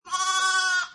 lamb.mp3